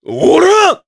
Gau-Vox_Attack3_jp.wav